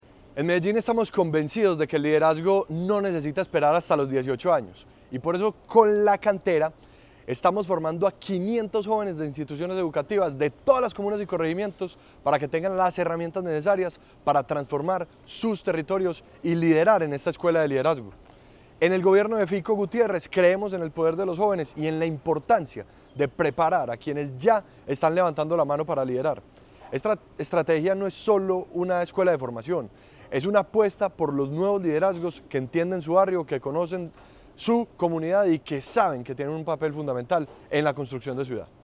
Declaraciones secretario de la Juventud, Ricardo Jaramillo La Cantera es un espacio de formación para acompañar y potenciar a los nuevos liderazgos que surgen de los Consejos de Participación.
Declaraciones-secretario-de-la-Juventud-Ricardo-Jaramillo.mp3